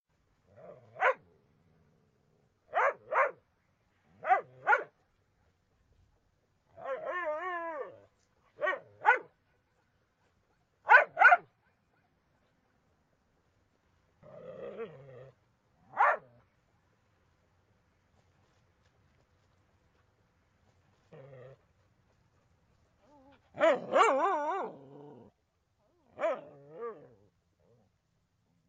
Dog Small-sound-HIingtone
dog-small-barking.mp3